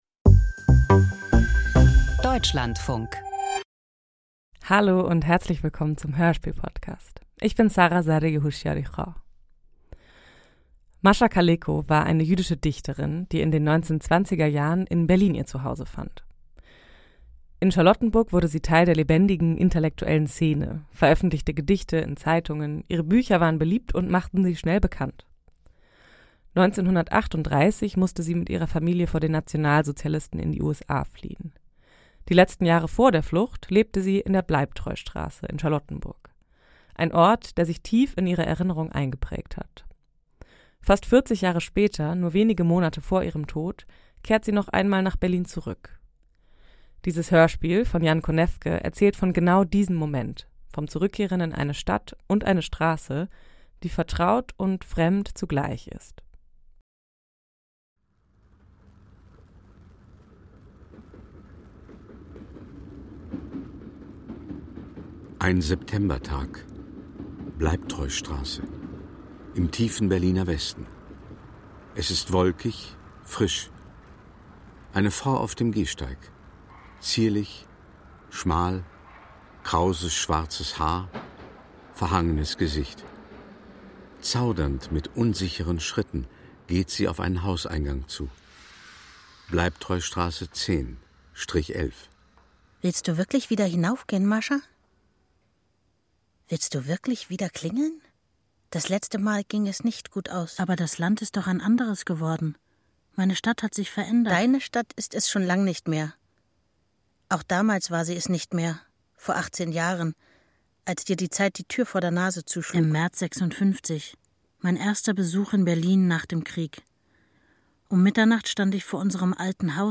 • Wort-Klang-Komposition • Keine Angst vor Quantenphysik! Hier läuft kein wissenschaftlicher Vortrag, sondern ein sinnlich erfahrbares Spiel mit Text und Musik, das klangvoll dazu einlädt, über das Verhältnis von Raum und Zeit nachzudenken.